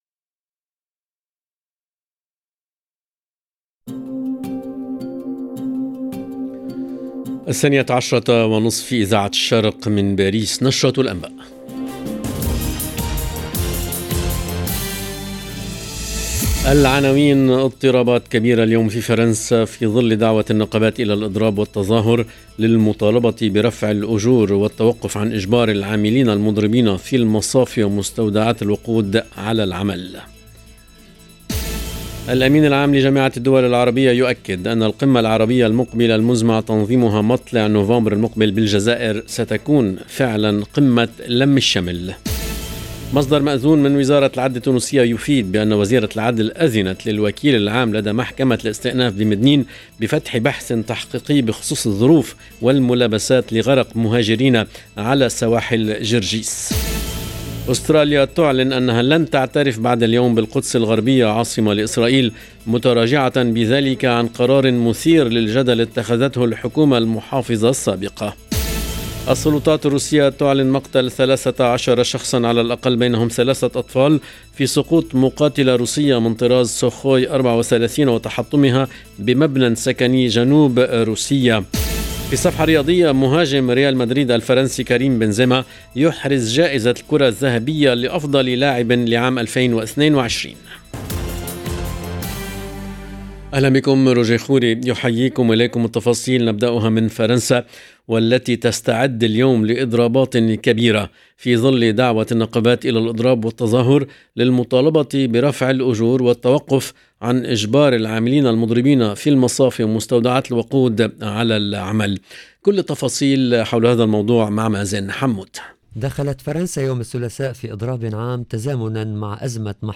LE JOURNAL EN LANGUE ARABE DE MIDI 30 DU 18/10/22